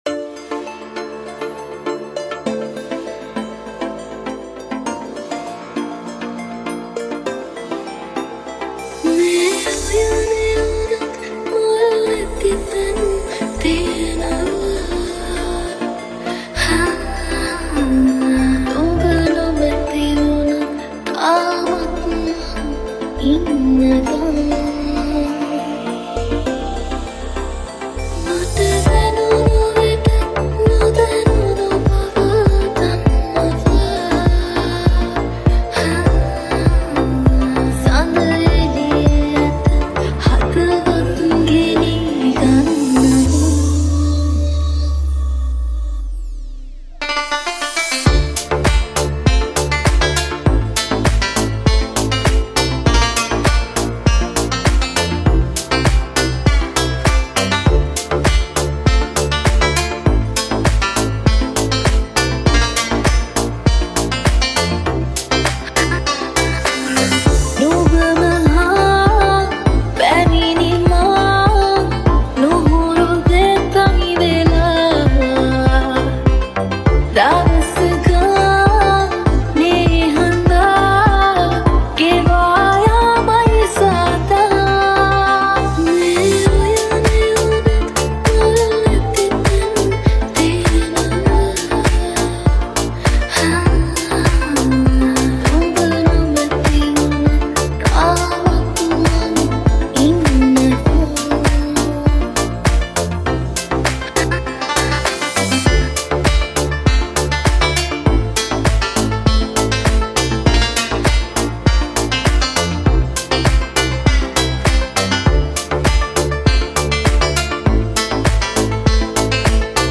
Progressive House Remix